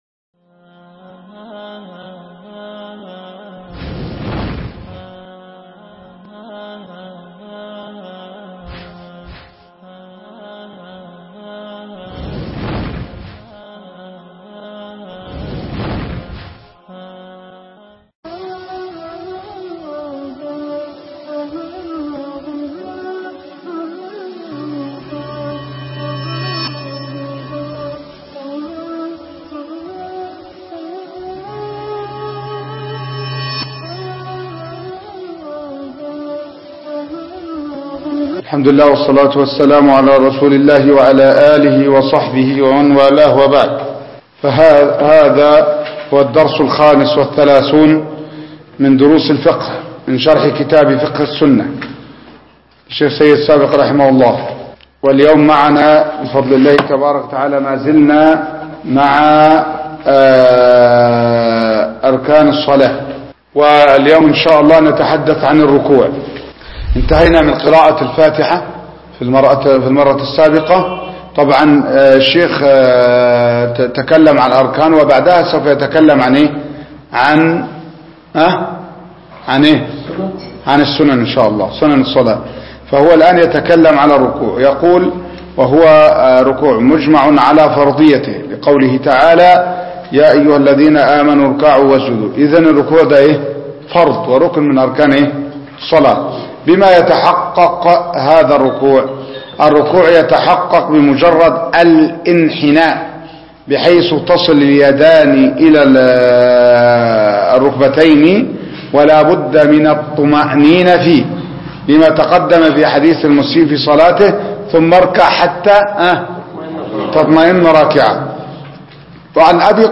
شرح كتاب فقه السنة الدرس 35